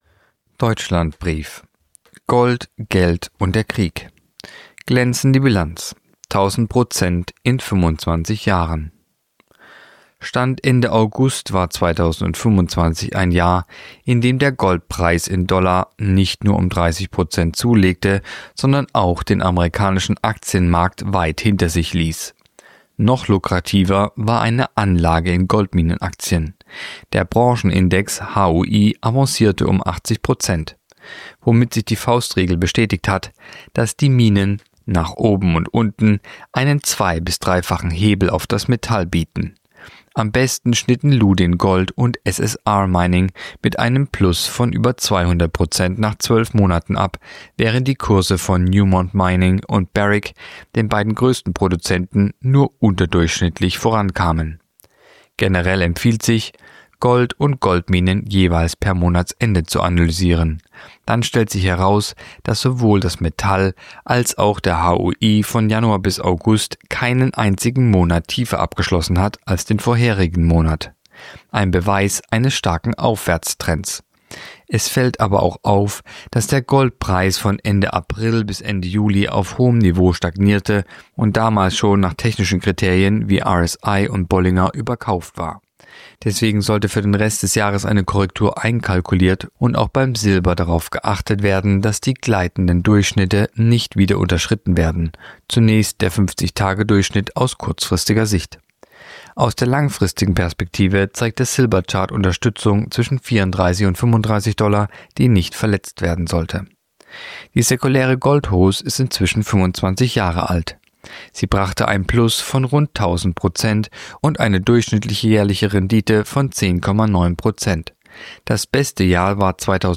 Artikel der Woche (Radio)Gold, Geld und der Krieg